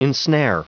Prononciation du mot ensnare en anglais (fichier audio)
Prononciation du mot : ensnare
ensnare.wav